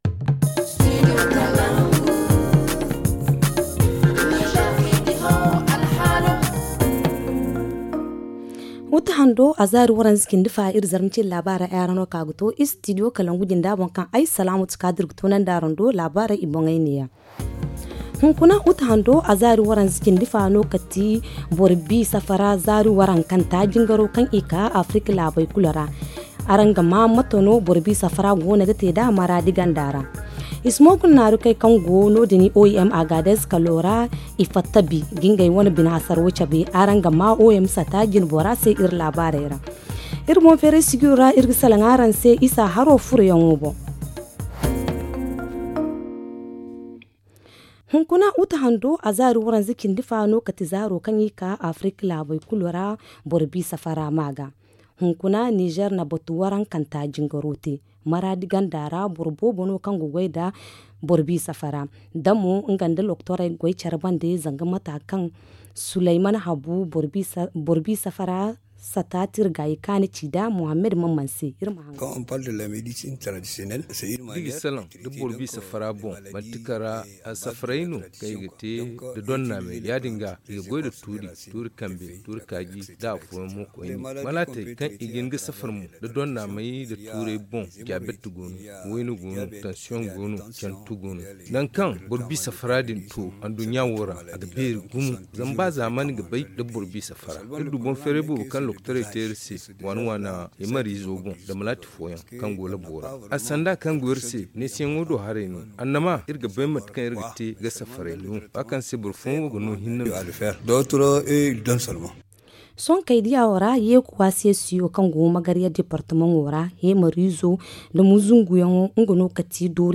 Le journal du jour 31 août 2022 - Studio Kalangou - Au rythme du Niger